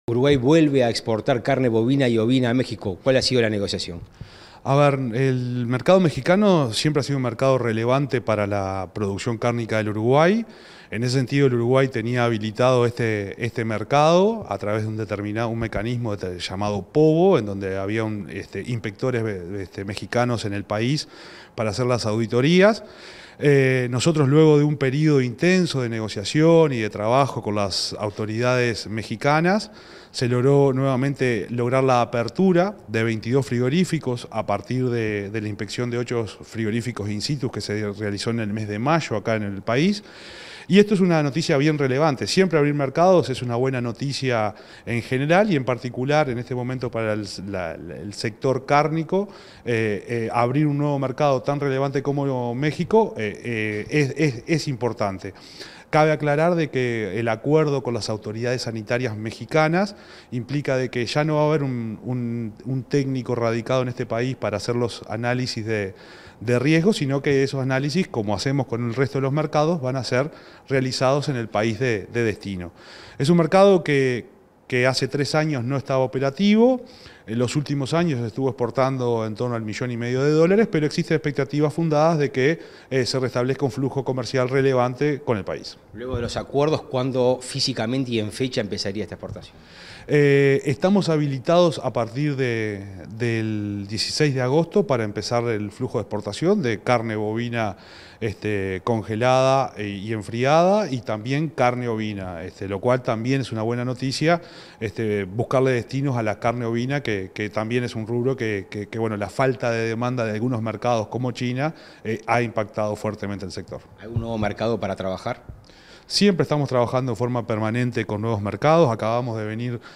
Entrevista al ministro interino de Ganadería, Agricultura y Pesca, Juan Ignacio Buffa